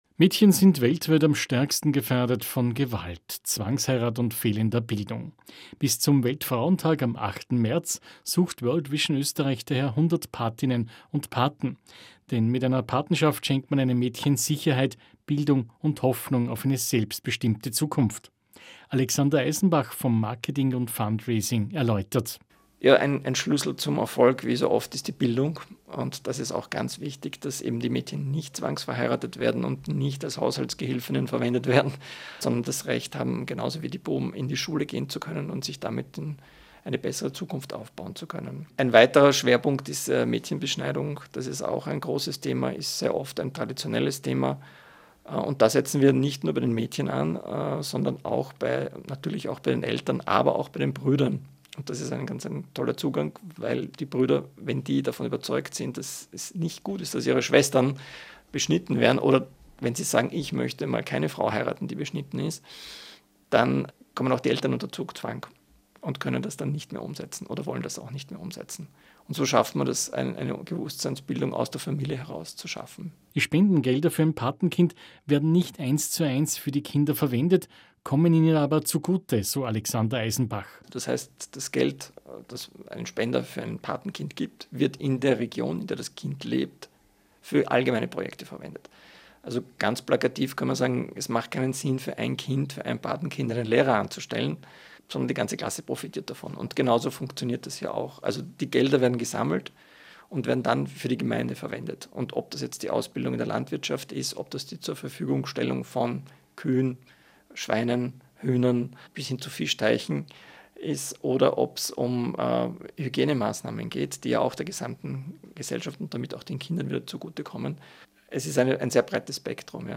Radiobeiträge zu unserer „100 Mädchen“ Kampagne